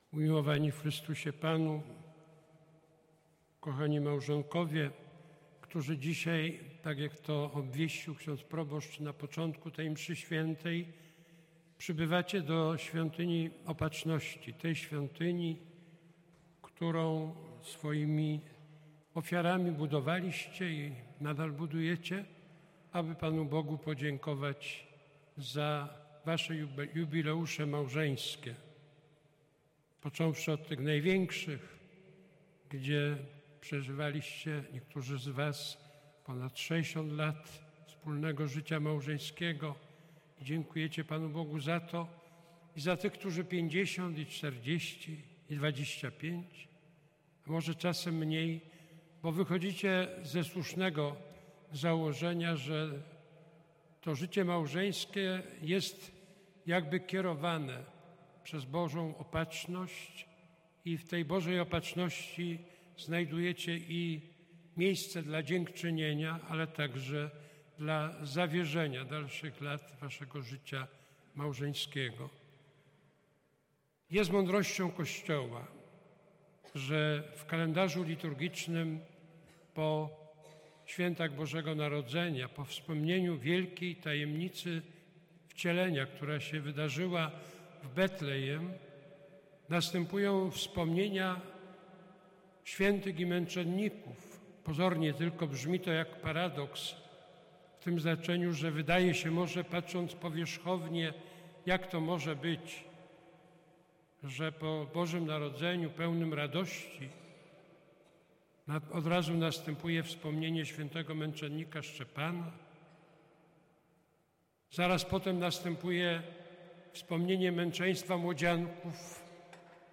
W sobotnie południe w Świątyni Opatrzności Bożej została odprawiona Eucharystia w intencji rodzin.
Kazanie wygłosił kardynał Kazimierz Nycz. Zwracając się do małżonków, podkreślił, że życie małżeńskie jest kierowane przez Bożą Opatrzność.